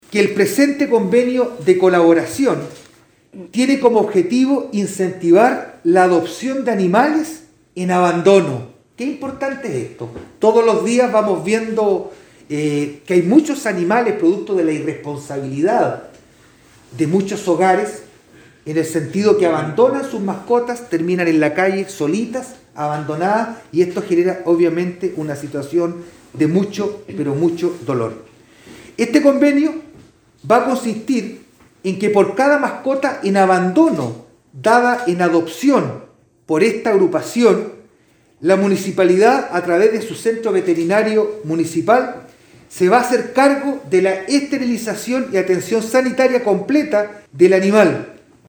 ALCALDE-VERA-HUMANADA-1.mp3